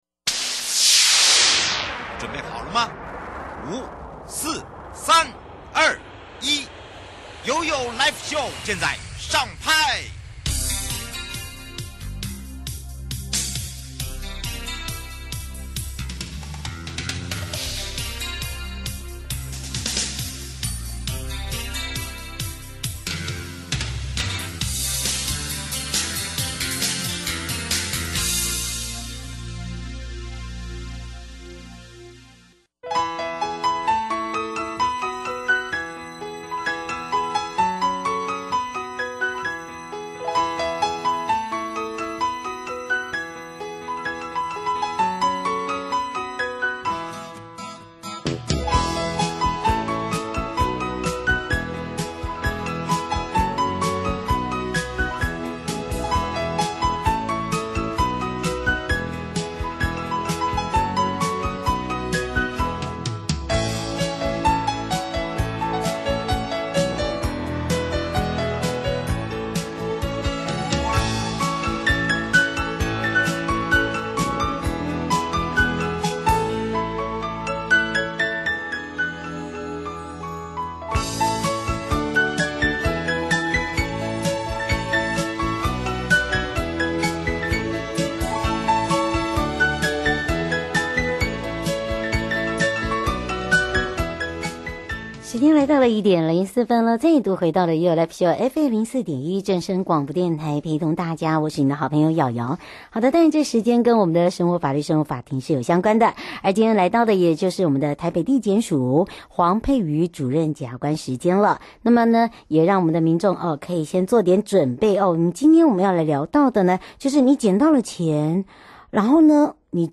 受訪者： 台北地檢黃珮瑜主任檢察官 節目內容： 撿到錢而據為己有的法律效果-撿到東西據為己有會有刑責嗎 ？